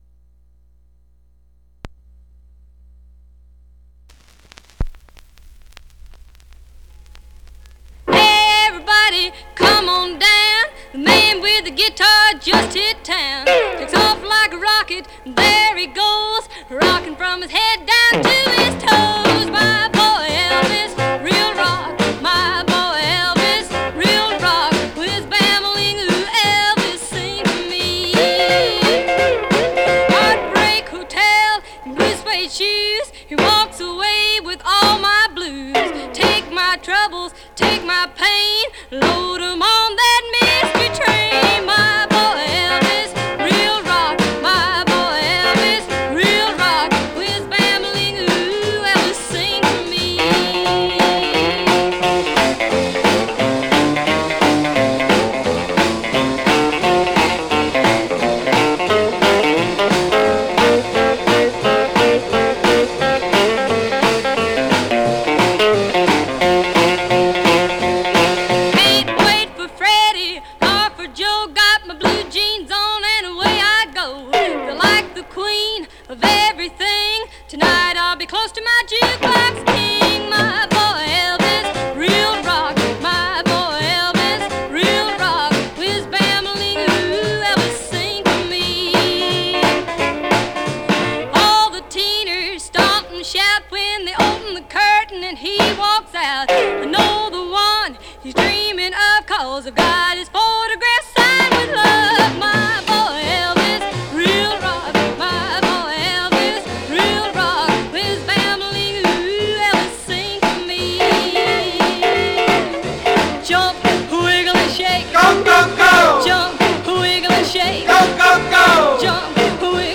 Condition Surface noise/wear Stereo/mono Mono
Rockabilly